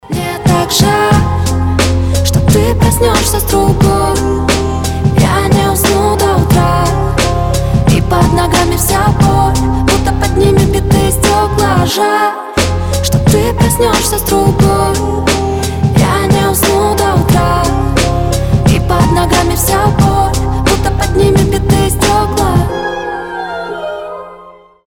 • Качество: 320, Stereo
лирика
Хип-хоп
грустные
красивый женский голос